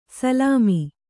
♪ salāmi